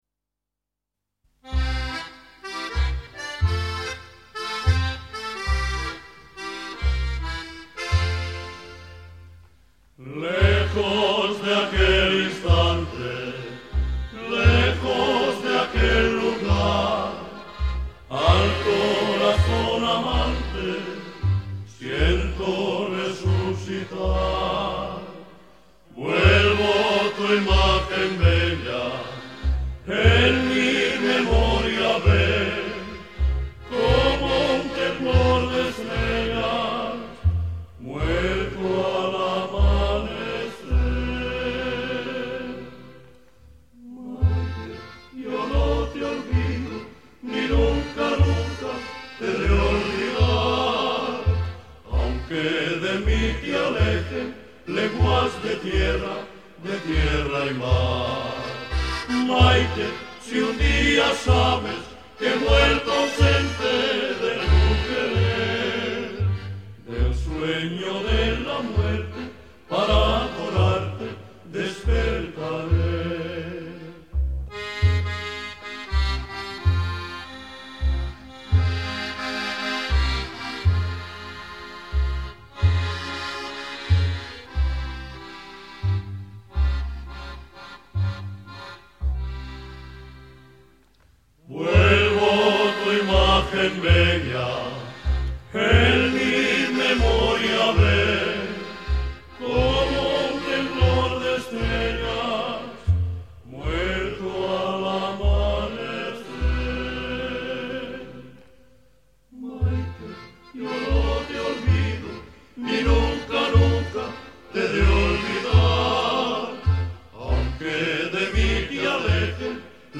Habaneras